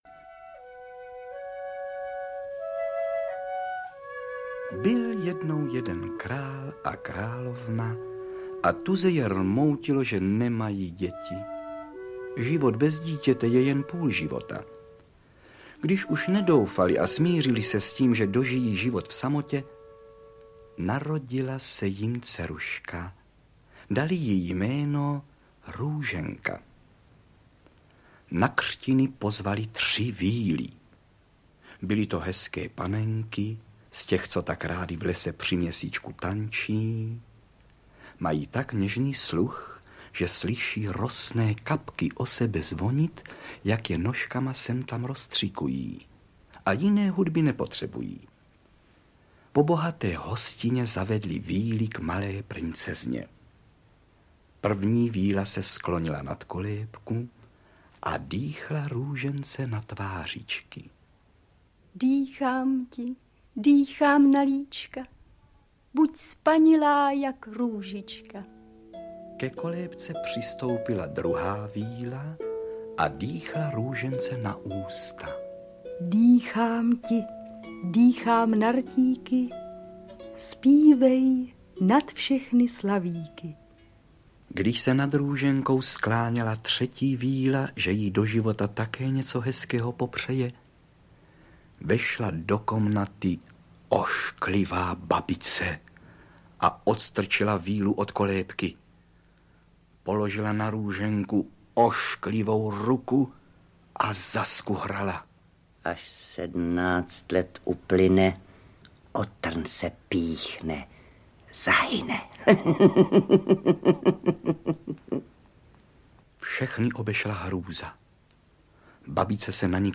V překrásném a nestárnoucím výběru veršů a pohádek Františka Hrubína a v laskavém a hravém podání Karla Högera, Františka Smolíka, Julie Charvátové, Václava Vosky a mnoha dalších se mohou děti setkat nejen s Jeníčkem a Mařenkou z veršované pohádky o…
Ukázka z knihy
• InterpretVáclav Voska, František Smolík, Karel Höger, Antonín Jedlička, Vlastimil Fišar, Julie Charvátová, Otýlie Beníšková, Stanislav Neumann